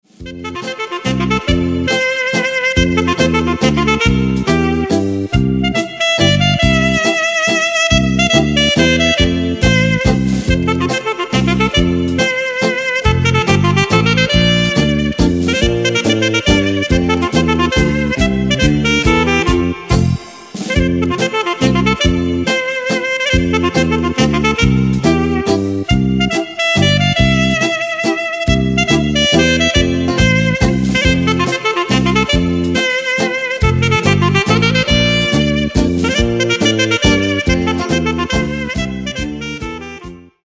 MAZURCA  (2.40)